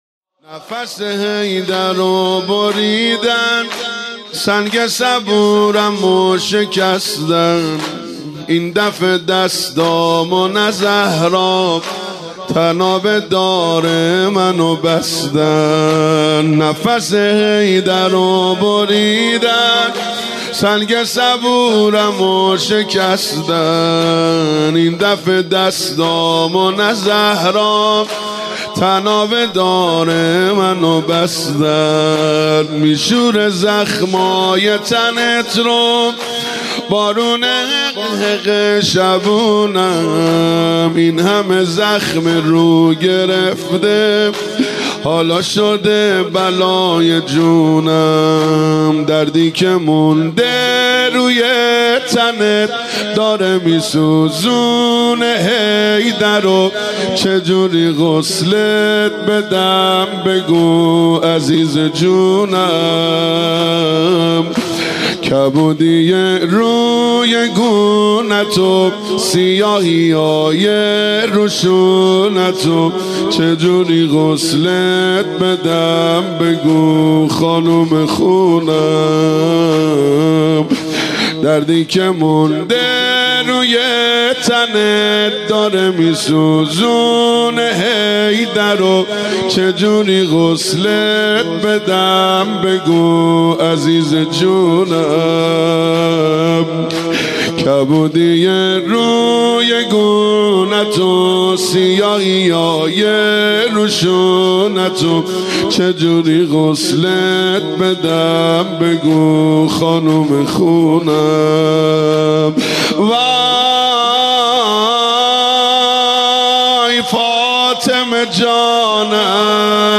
دانلود مداحی دلمو سوزونده غربت بقیع - دانلود ریمیکس و آهنگ جدید